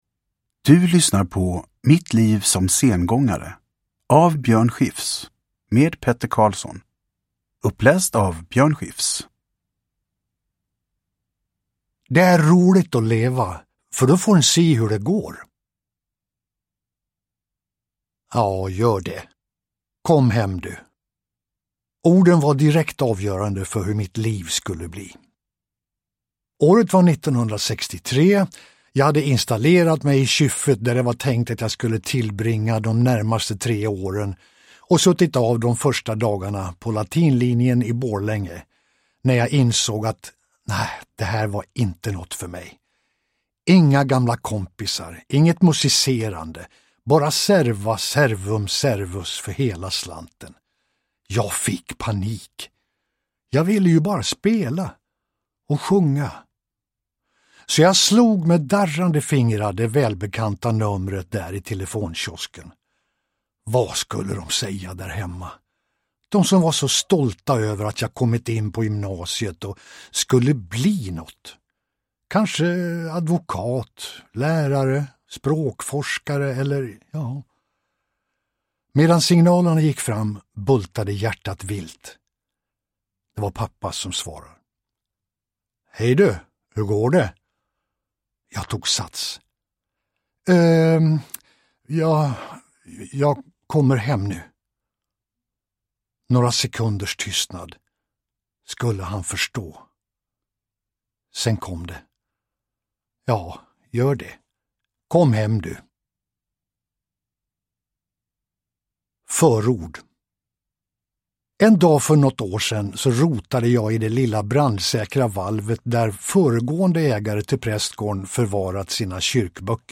Uppläsare: Björn Skifs
Ljudbok